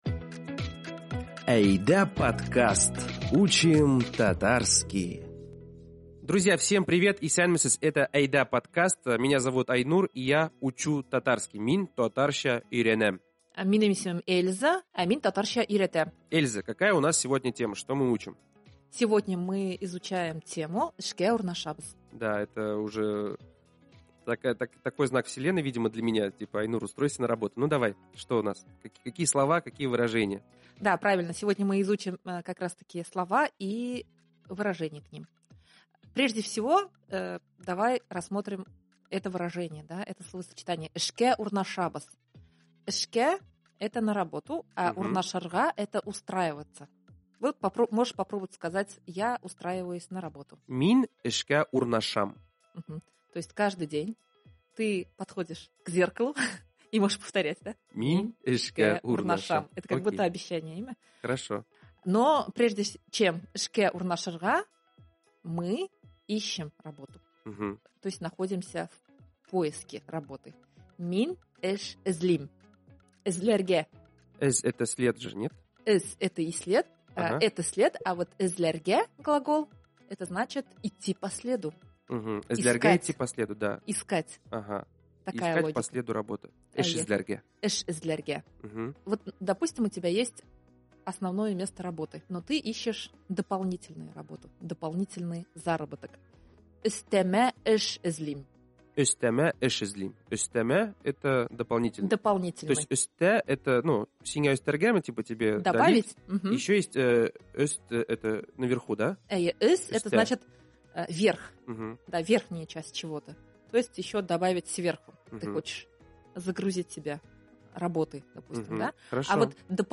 Изучаем татарский в лёгком формате разговоров.